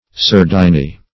surdiny - definition of surdiny - synonyms, pronunciation, spelling from Free Dictionary Search Result for " surdiny" : The Collaborative International Dictionary of English v.0.48: Surdiny \Surd"i*ny\, n. A sardine.